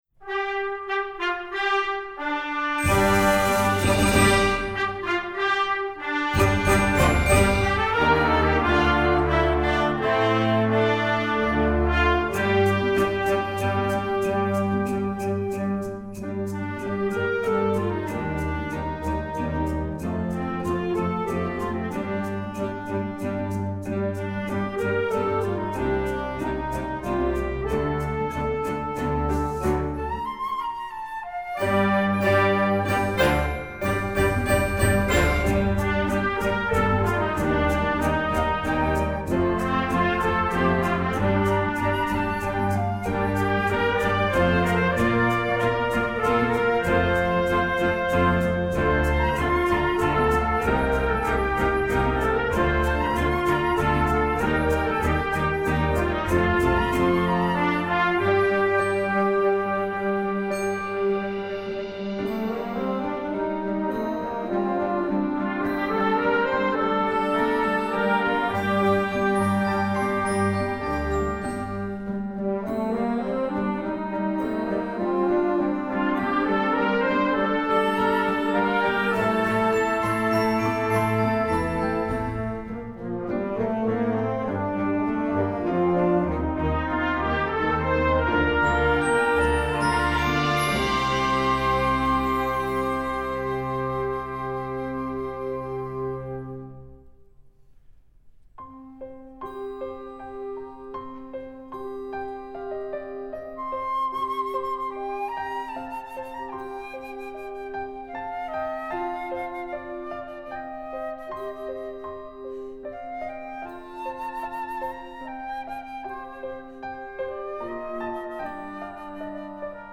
Instrumentation: concert band
pop, rock, instructional